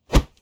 Close Combat Swing Sound 45.wav